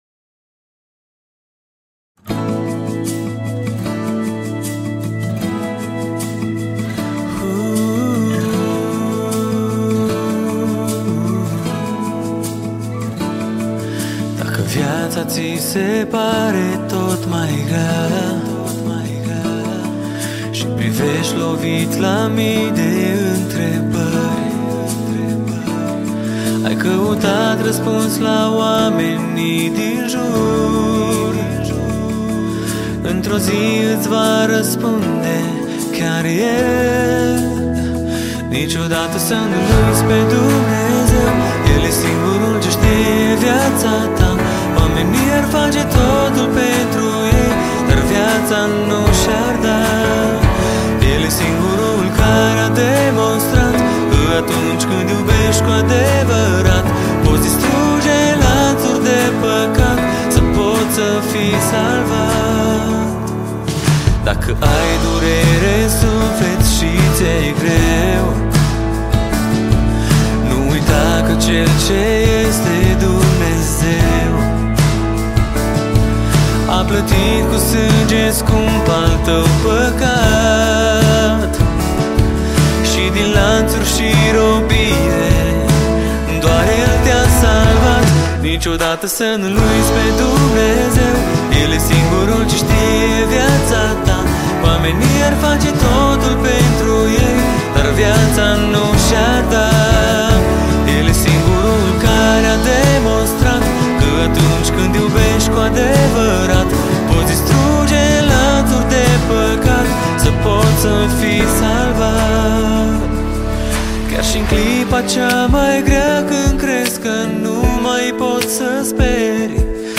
Categoria: Muzica Crestina